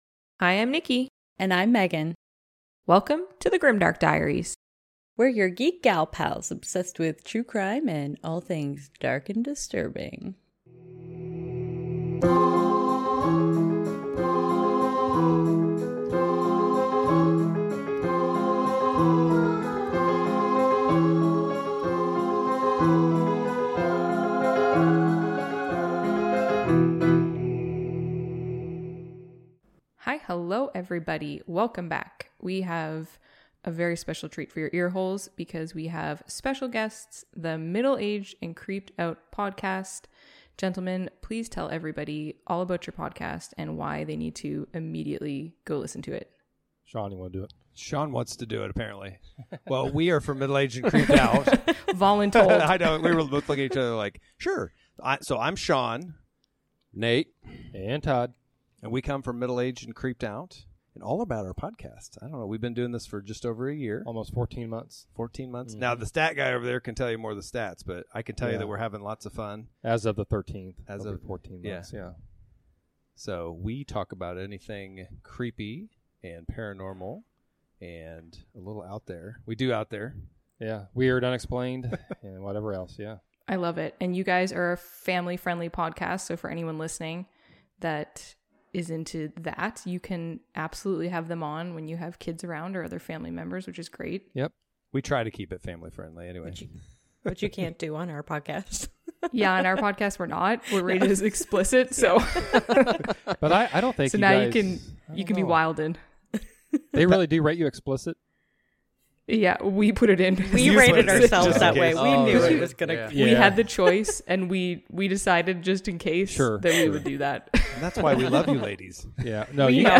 Bonus Episode - The Grimdark Diaries Interview with MAACO